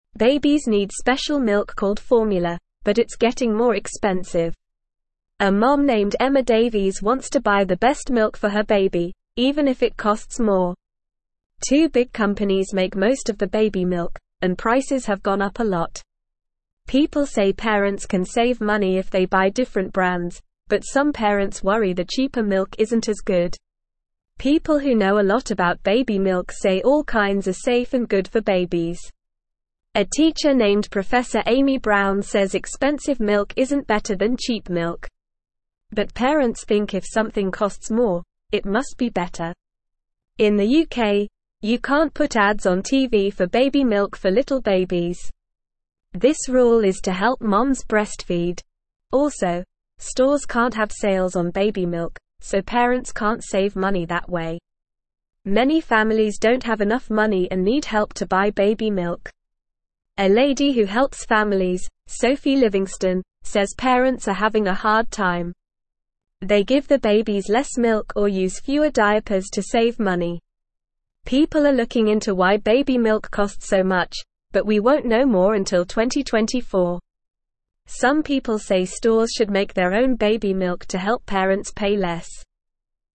Normal
English-Newsroom-Lower-Intermediate-NORMAL-Reading-Expensive-Baby-Food-Cheaper-Options-Are-Good.mp3